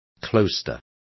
Also find out how clausuras is pronounced correctly.